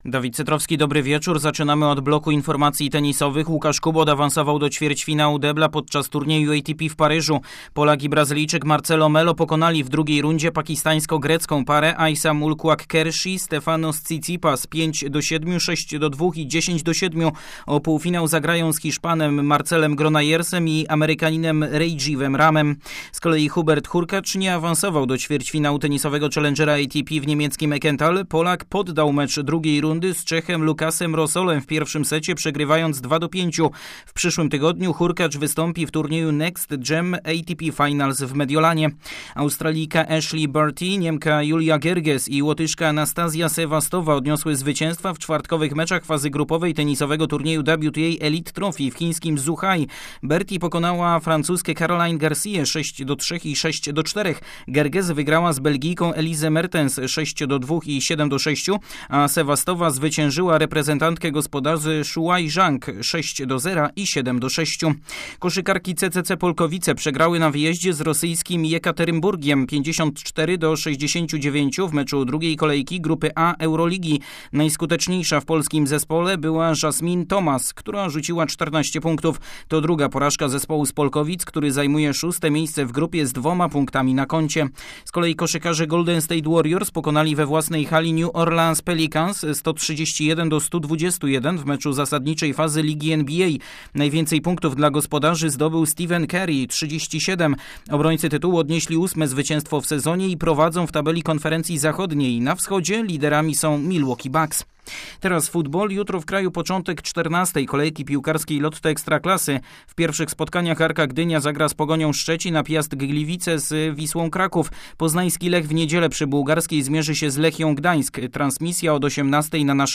01.11. serwis sportowy godz. 19:05